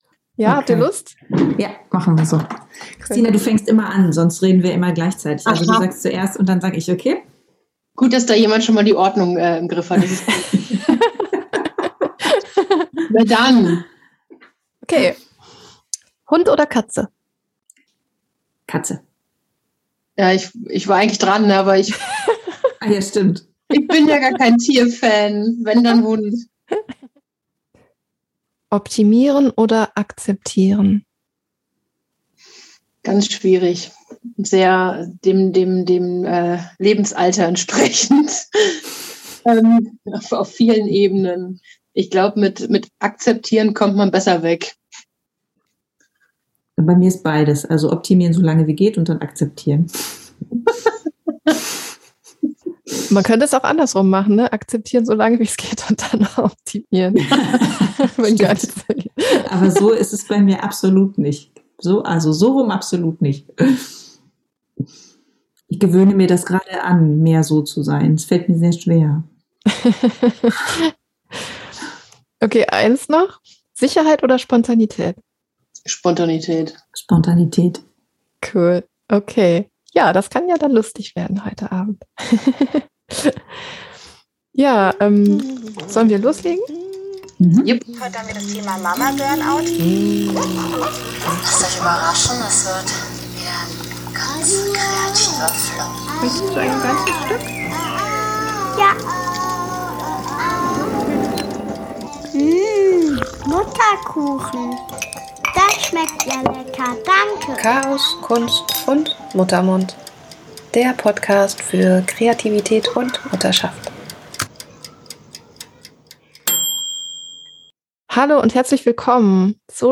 Diese Folge ist ein virtuelles Mini-Klassentreffen.